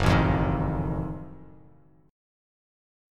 F#mM13 chord